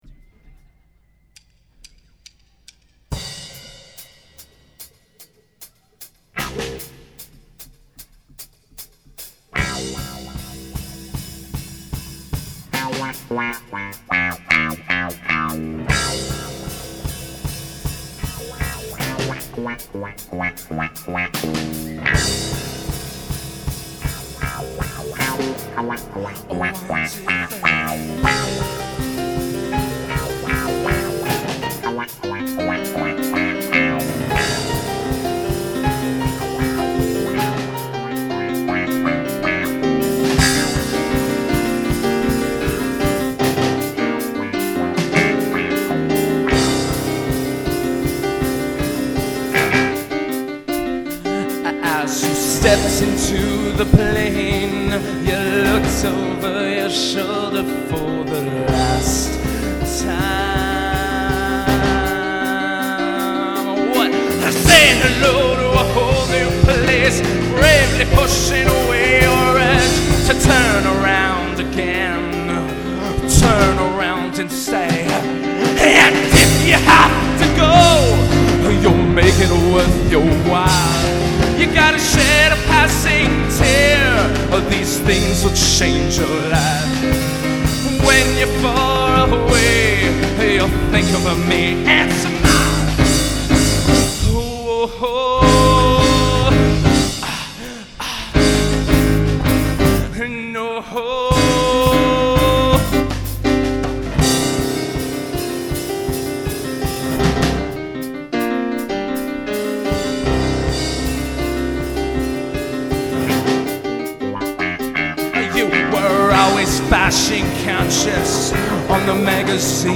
Full Band soundboard recording
Bass
Drums